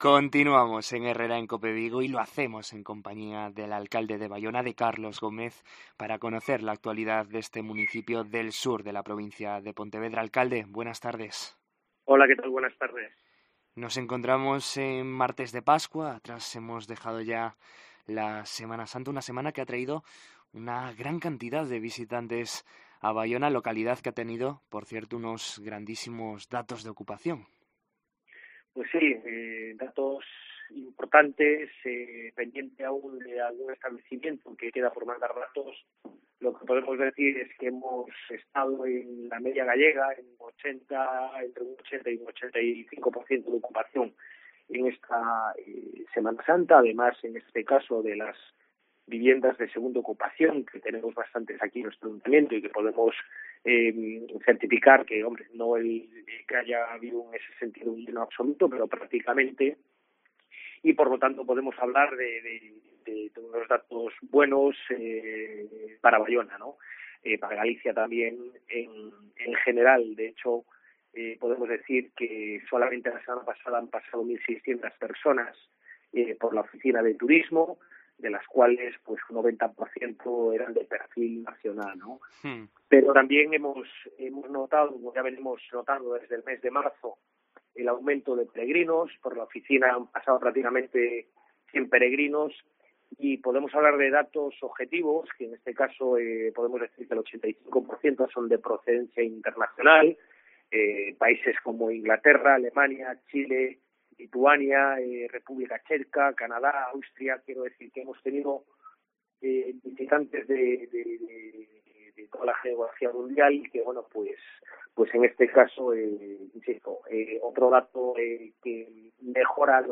Entrevista a Carlos Gómez, alcalde de Baiona: "El 8 de mayo Baiona vuelve a organizar un safari"
En Herrera en COPE Vigo hablamos con el alcalde de Baiona, Carlos Gómez, para conocer la actualidad de este municipio del sur de la provincia de Pontevedra